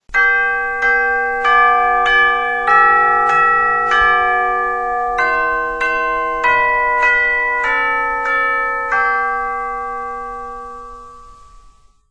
chimetwinkle.wma